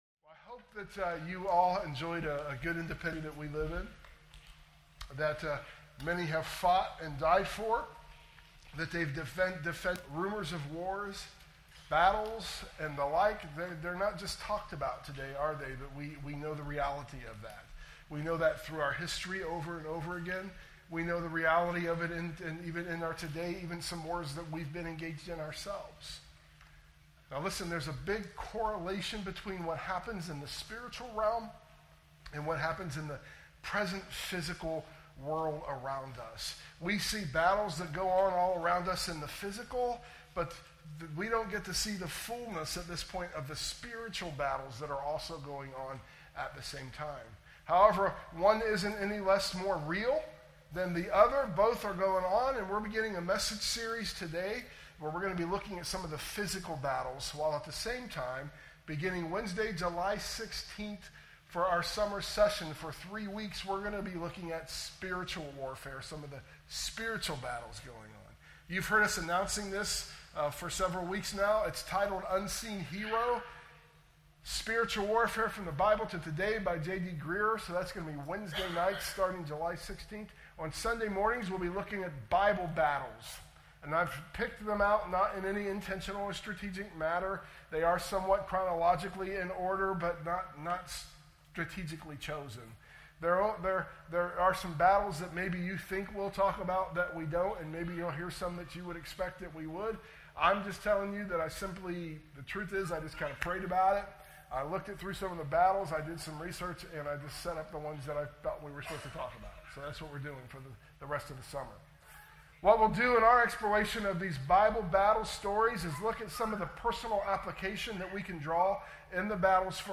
We apologize for a little bit of the sound skipping in the audio version of the message this week.
sermon_audio_mixdown_7_6_25.mp3